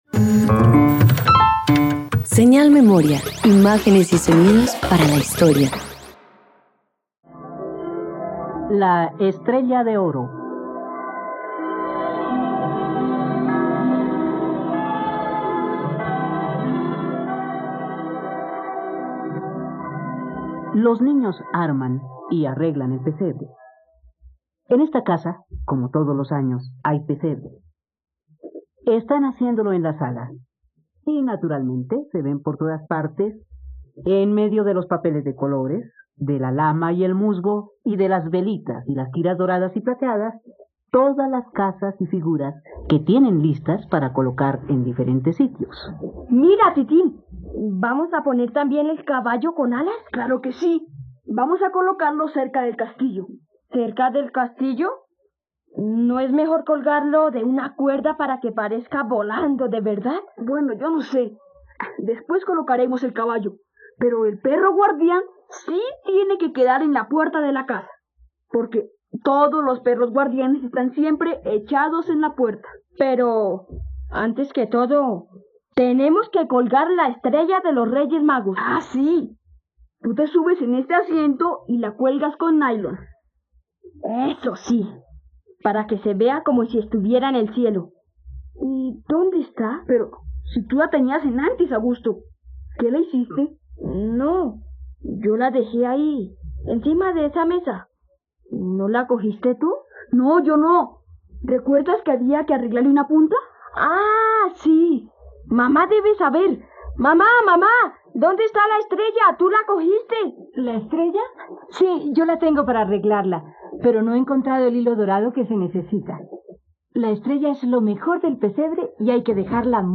La estrella de oro - Radioteatro dominical | RTVCPlay
..Radioteatro. Escucha la adaptación del cuento de Navidad "La estrella de oro" en la plataforma de streaming de todos los colombianos: RTVCPlay.